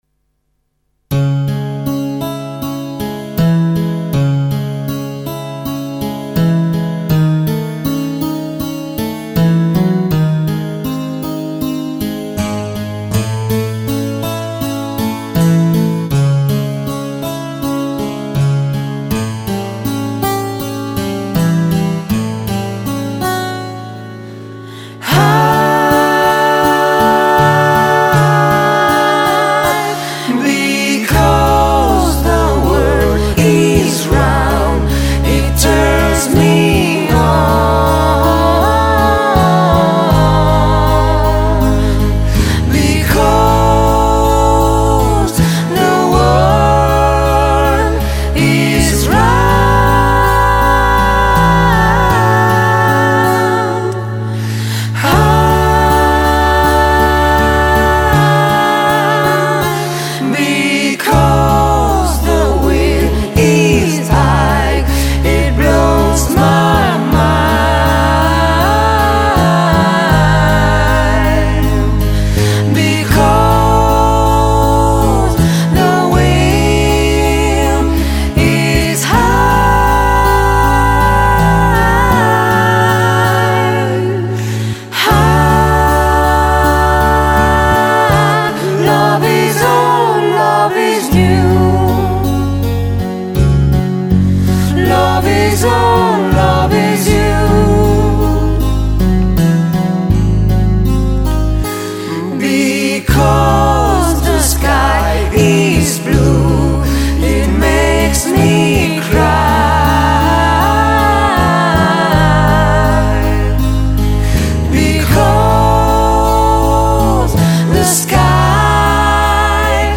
cover di vario genere riarrangiate in chiave acustica